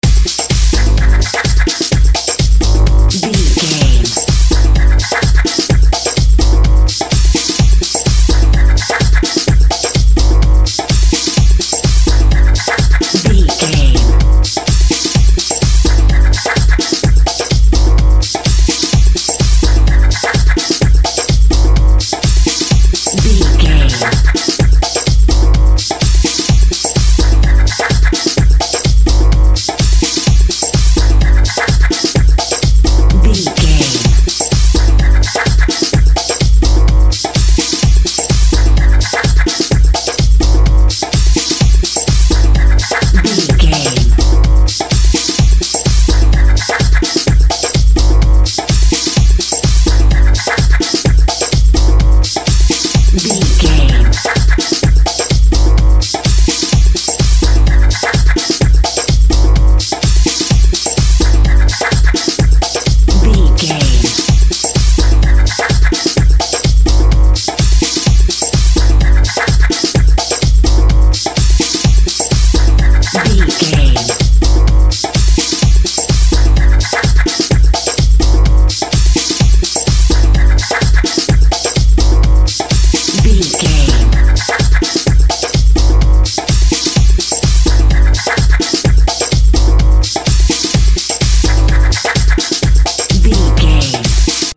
Epic / Action
Fast paced
Atonal
futuristic
energetic
driving
dark
intense
drums
drum machine
synthesiser
Drum and bass
break beat
electronic
sub bass
synth lead
synth bass